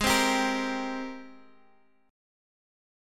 AbM9 Chord
Listen to AbM9 strummed